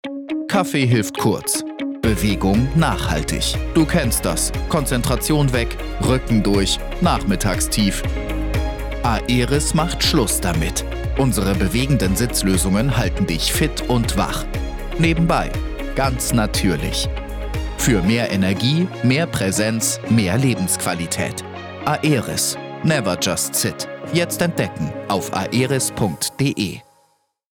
Sprecher und Voice-Over-Künstler mit einer jungen, frischen Stimme – professionell und wandelbar.
Sprechprobe: Industrie (Muttersprache):